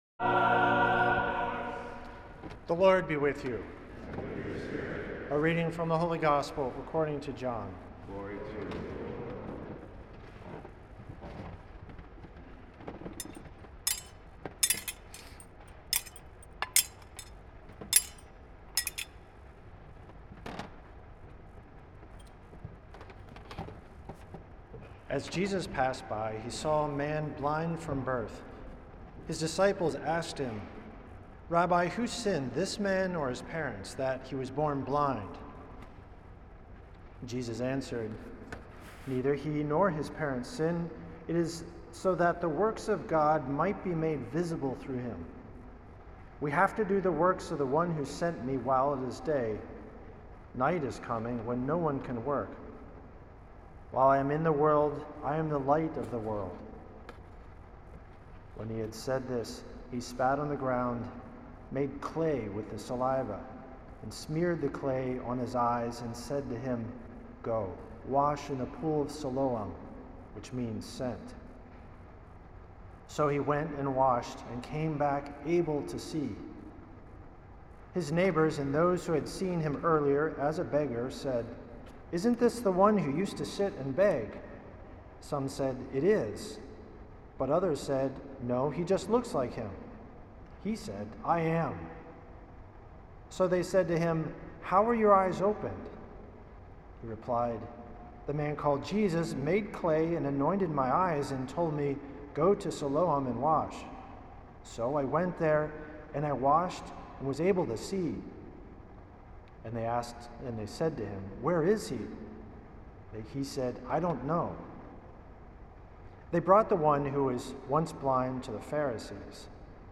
at St. Patrick’s Old Cathedral in NYC on March 15th